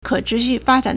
可持续发展 (可持續發展) kě chíxù fāzhǎn
ke3chi2xu4fa1zhan3.mp3